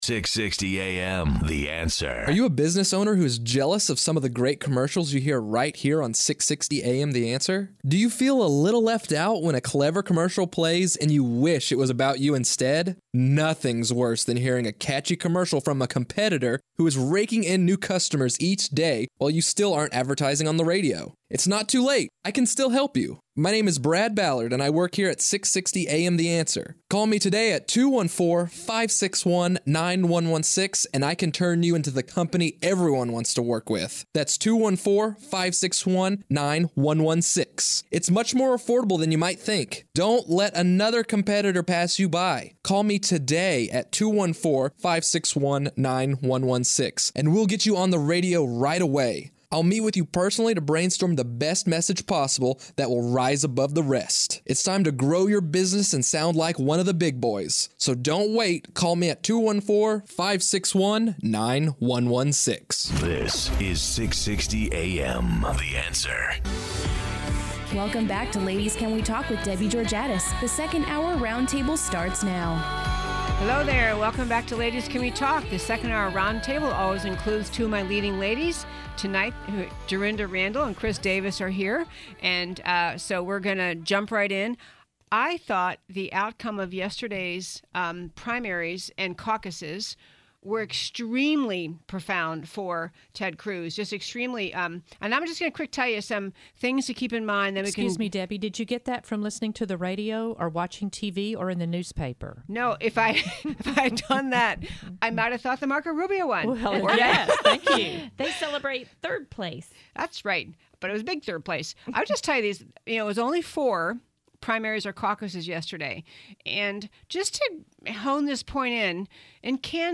Radio Show Podcasts